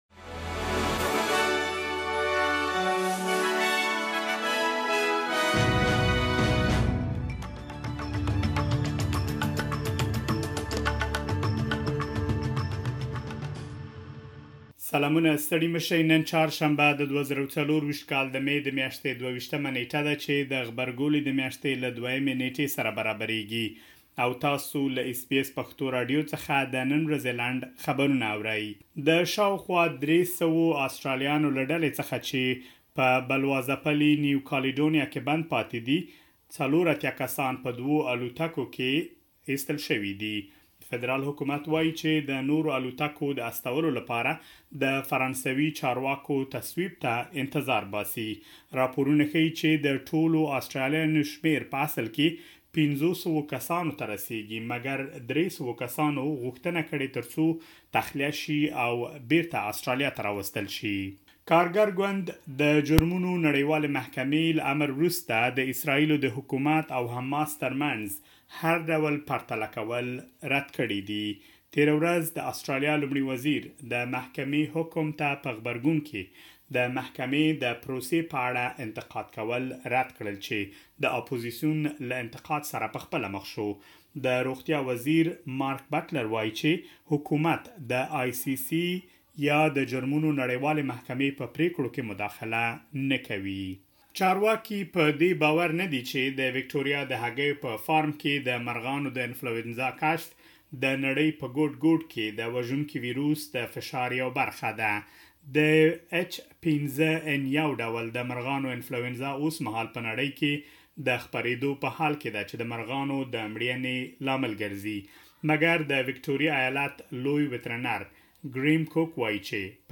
د اس بي اس پښتو د نن ورځې لنډ خبرونه|۲۲ مې ۲۰۲۴
د اس بي اس پښتو د نن ورځې لنډ خبرونه دلته واورئ.